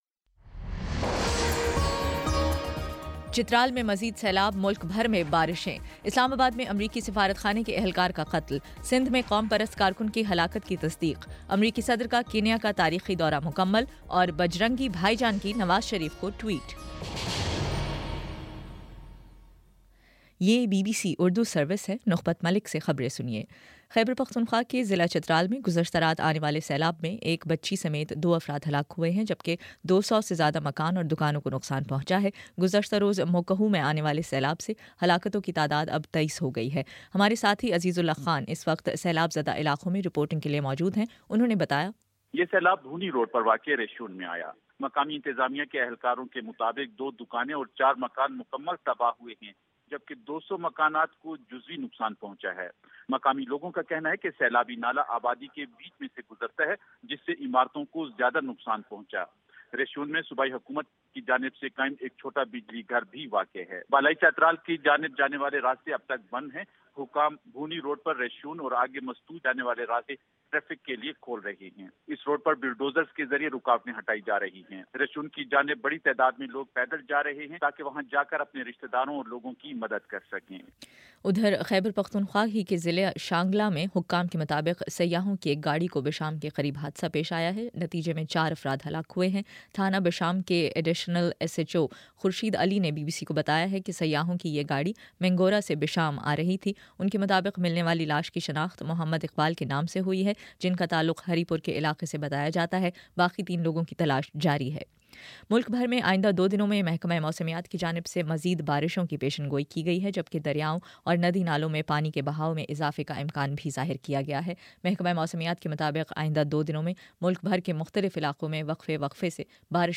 جولائی 26: شام چھ بجے کا نیوز بُلیٹن